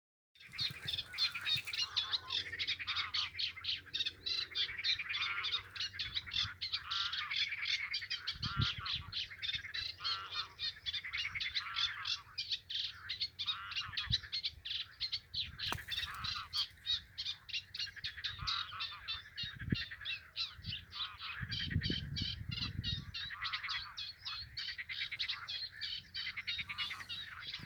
Птицы -> Славковые ->
тростниковая камышевка, Acrocephalus scirpaceus
СтатусПоёт